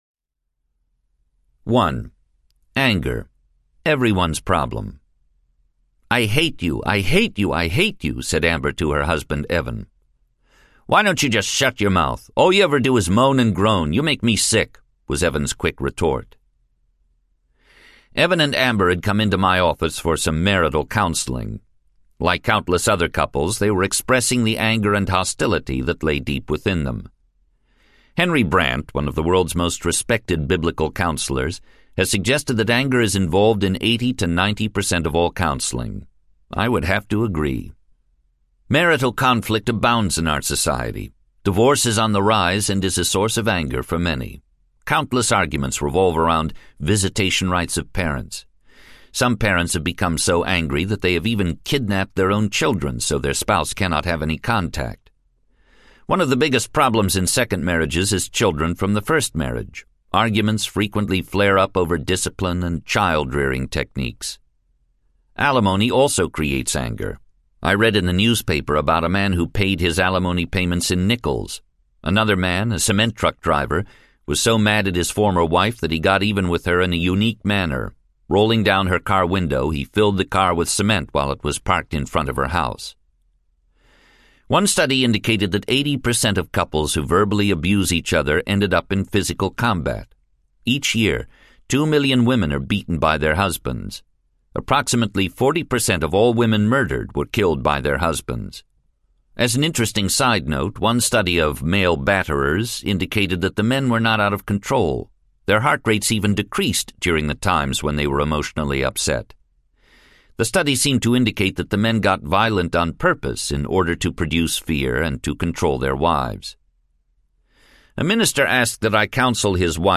Anger Is a Choice Audiobook
Narrator
5.2 Hrs. – Unabridged